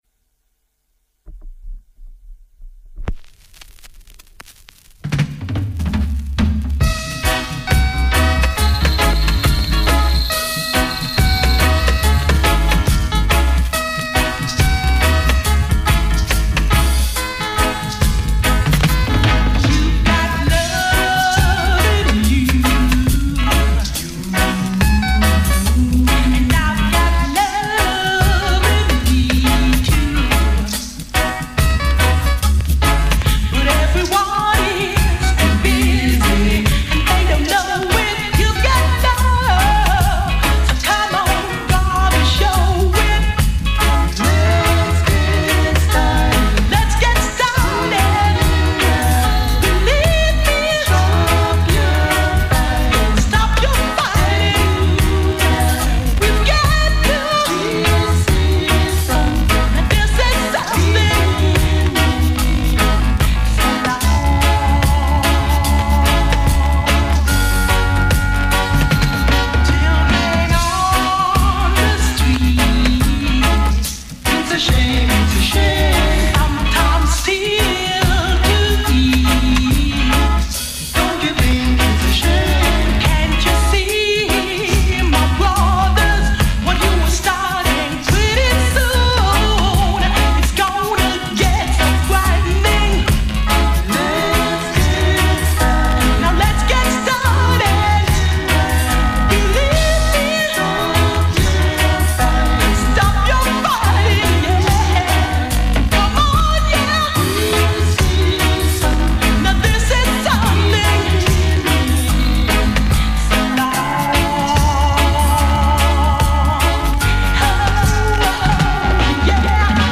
roots & culture